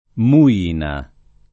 [ mu- & na ]